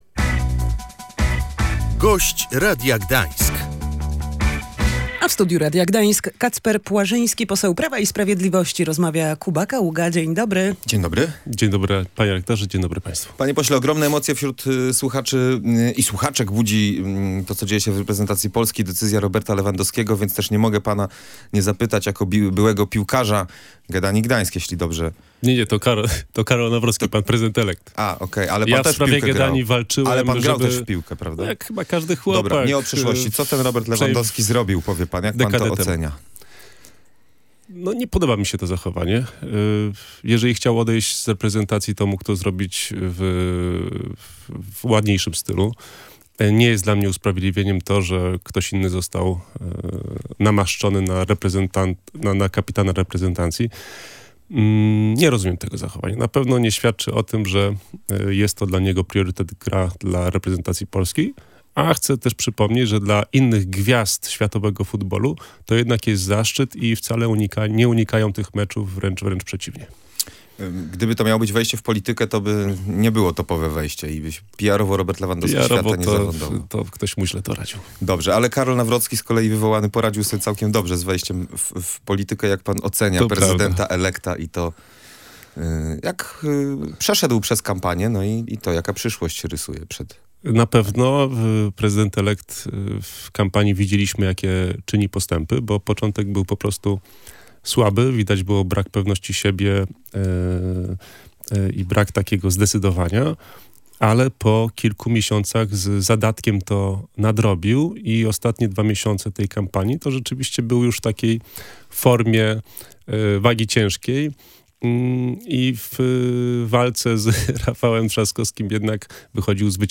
Początek kampanii wyborczej Karola Nawrockiego był niepewny, ale w bezpośredniej konfrontacji z Rafałem Trzaskowskim zyskiwał przewagę – ocenił Kacper Płażyński, poseł Prawa i Sprawiedliwości, który był Gościem Radia Gdańsk.